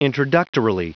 Prononciation du mot introductorily en anglais (fichier audio)
Prononciation du mot : introductorily